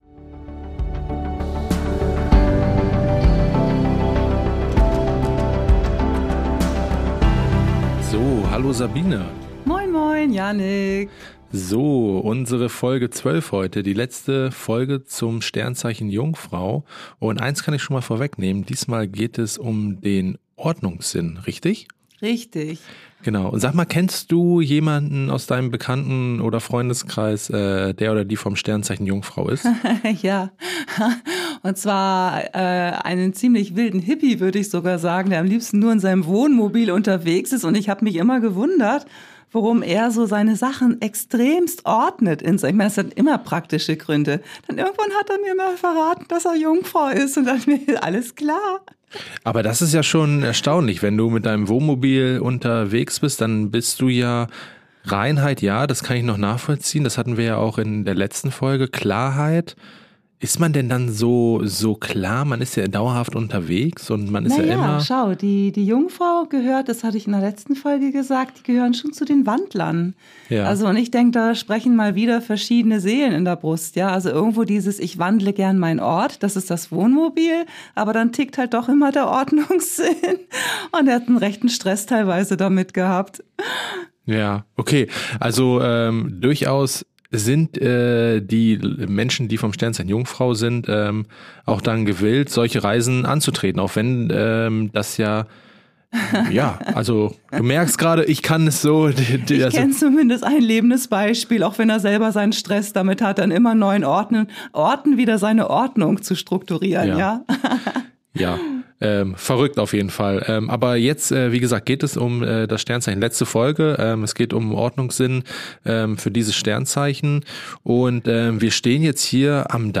Im Podcast erlebst du diese Orte hautnah mit authentischen Geräuschen und konkreten Anleitungen, die du vor Ort oder zuhause anwenden kannst.